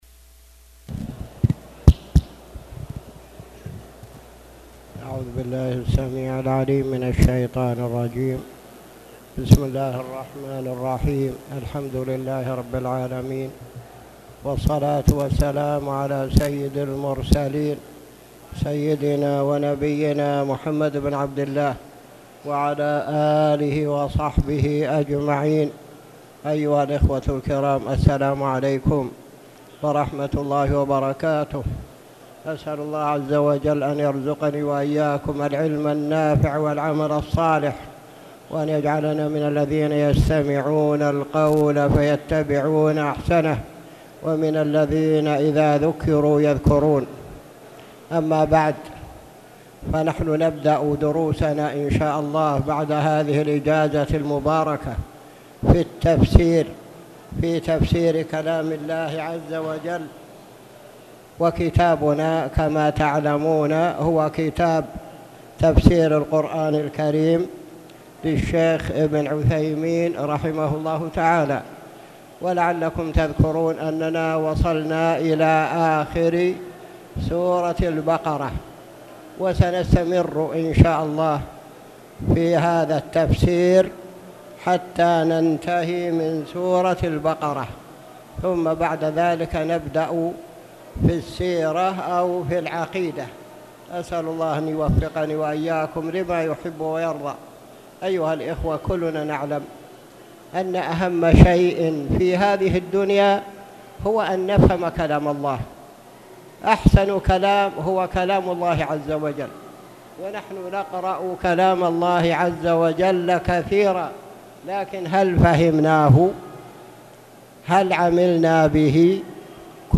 تاريخ النشر ٢٣ ذو الحجة ١٤٣٧ هـ المكان: المسجد الحرام الشيخ